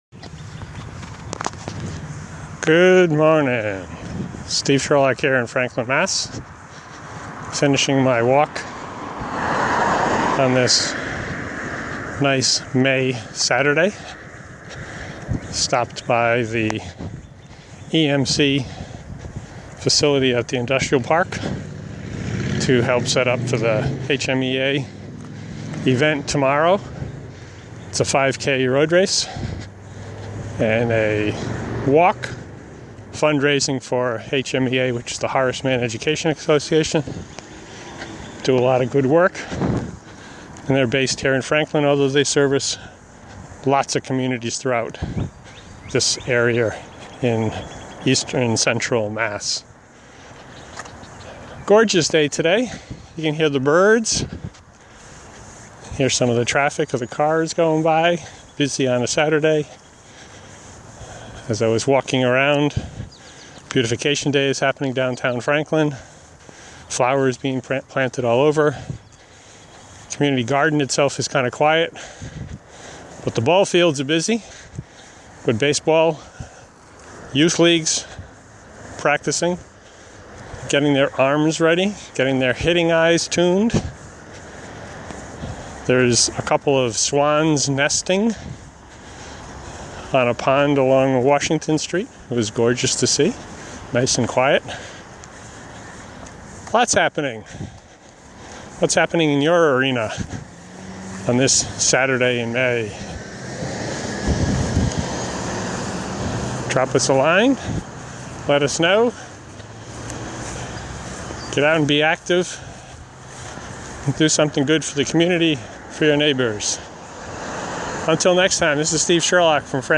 reporting by walking around
the walk Saturday morning found lots of action around Franklin